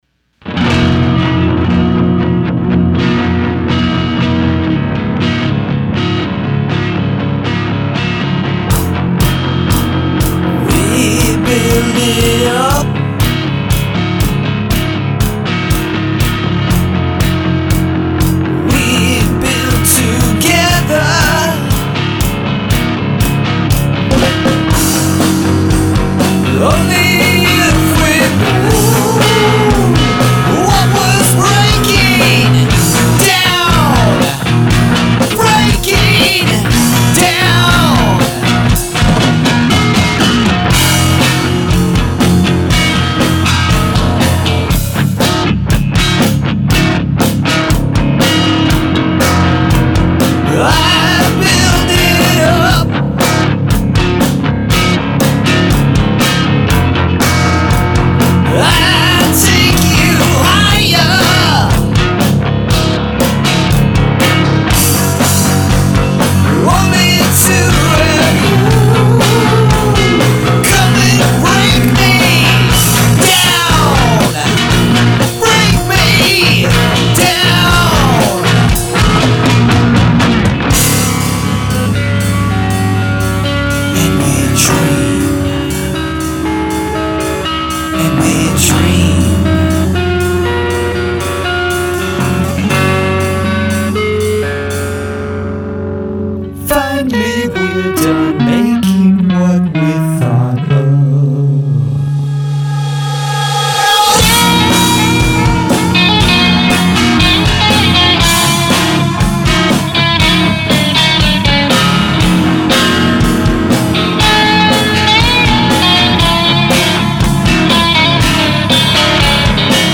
in his home studio